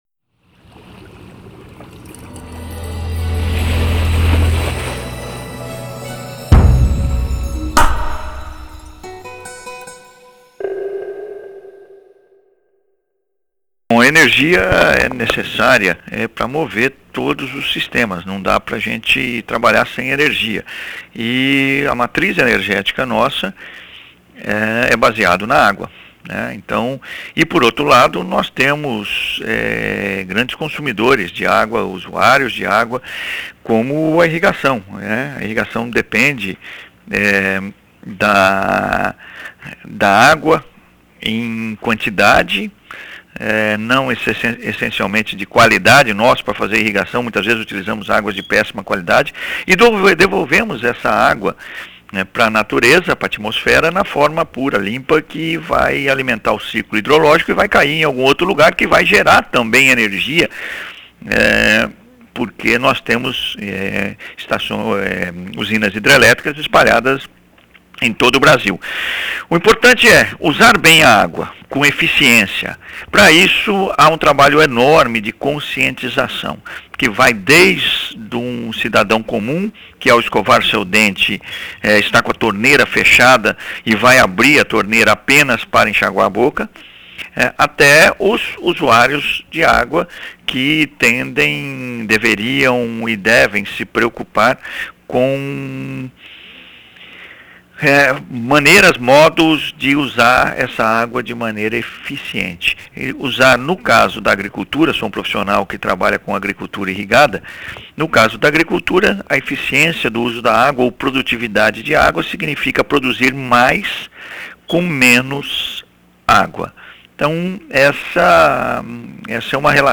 Ouça a entrevista na íntegra .